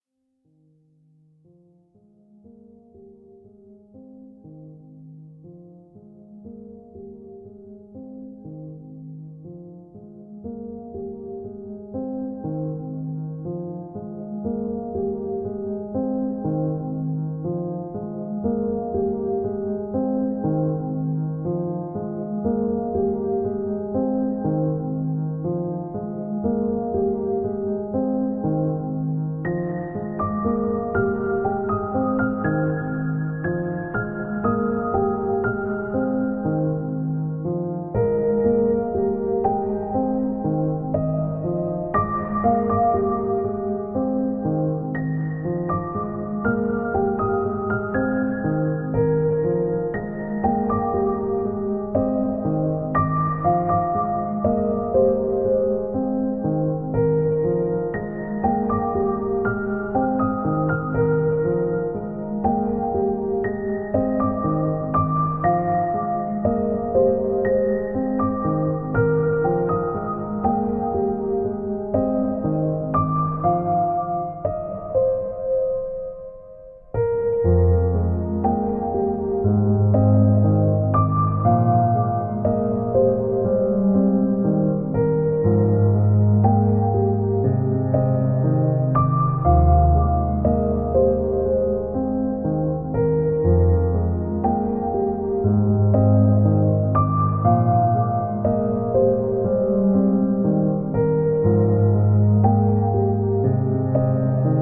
FugueMachine sequencing iGrandPiano on iPad.
I trimmed the audio a bit since it was too long… also, it has a fade in, so it takes a moment to catch up (also a good tip for your custom-made alarms, imho :nerd_face: )